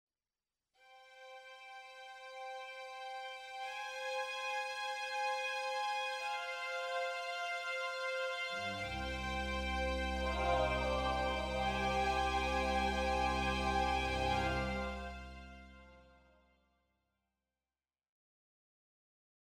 Аудиокнига Отметка на шкале – избранное | Библиотека аудиокниг